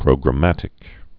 (prōgrə-mătĭk)